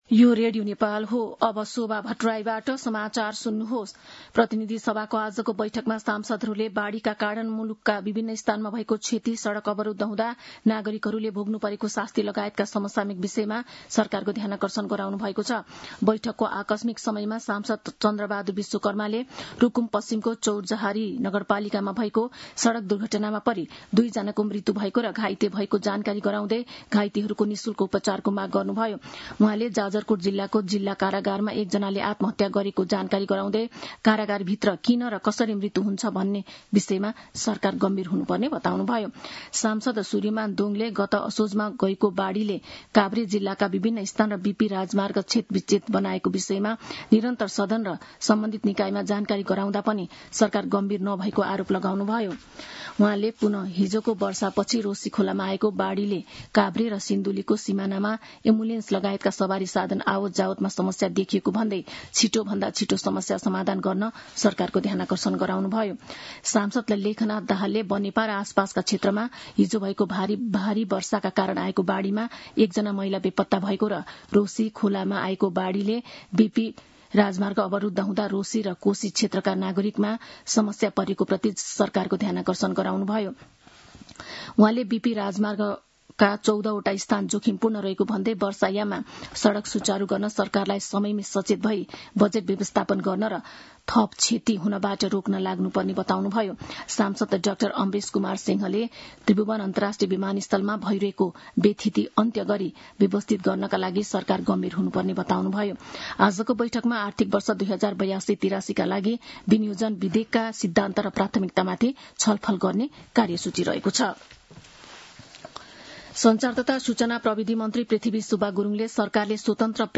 मध्यान्ह १२ बजेको नेपाली समाचार : ३० वैशाख , २०८२